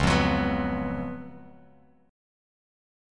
C#11 chord